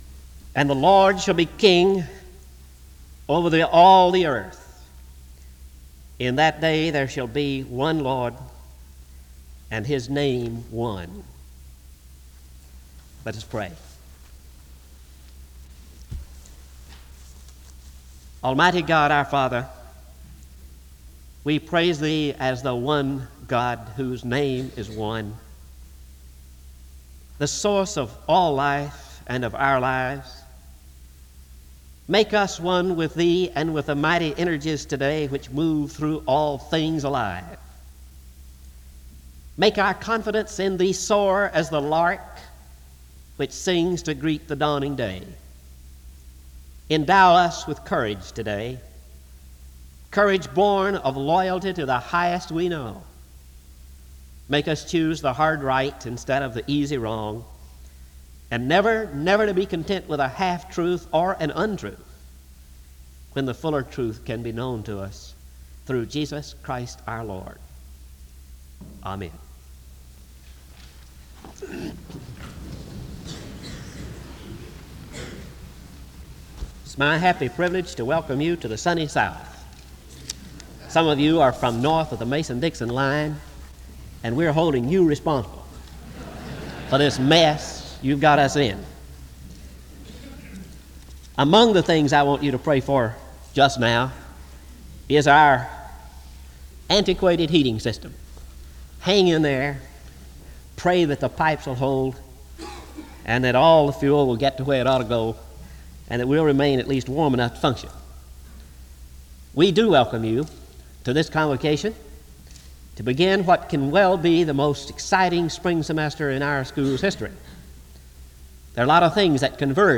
SEBTS Convocation